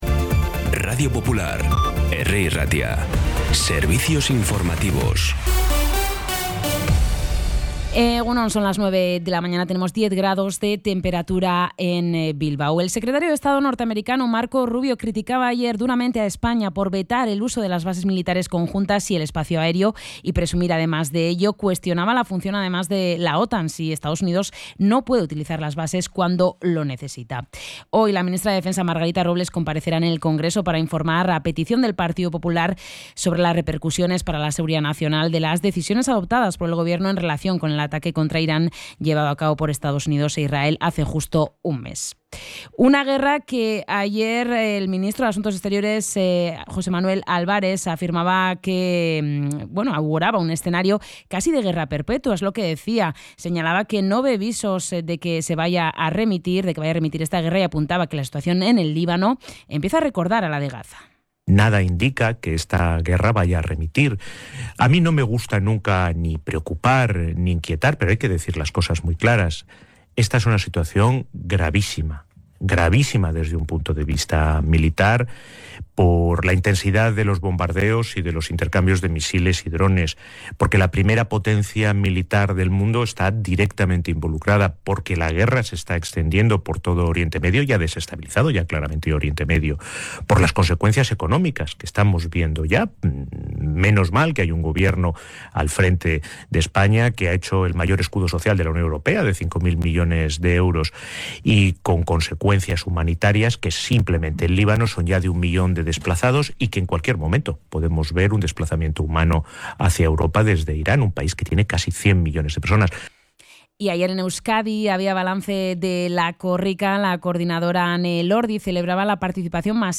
La última hora más cercana, de proximidad, con los boletines informativos de Radio Popular.
Los titulares actualizados con las voces del día. Bilbao, Bizkaia, comarcas, política, sociedad, cultura, sucesos, información de servicio público.